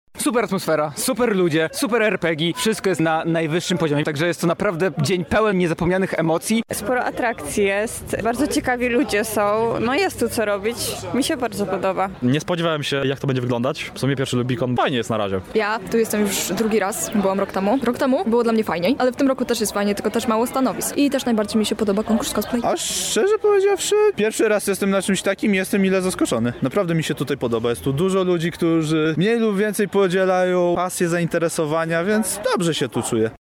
Zapytaliśmy gości festiwalu, co sądzą o tegorocznej edycji: